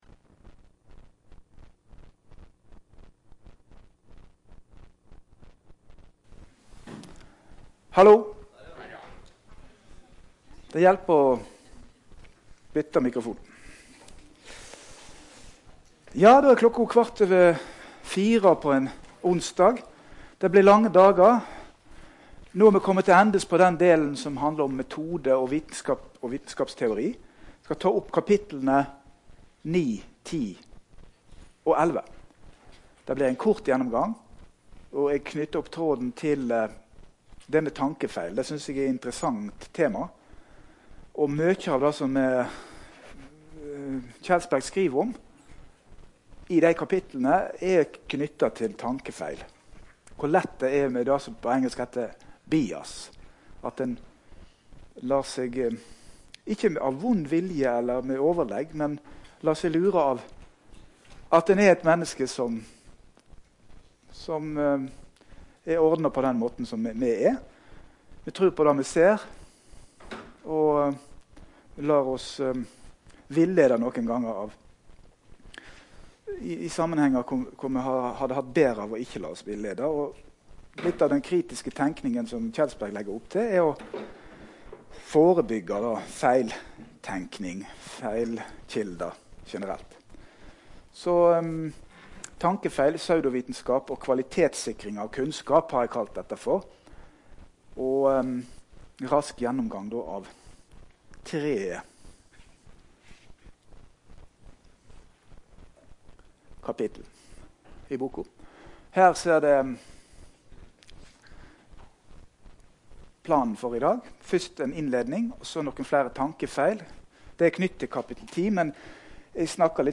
IFR forelesning - Alle
Rom: Smaragd 1 (S206)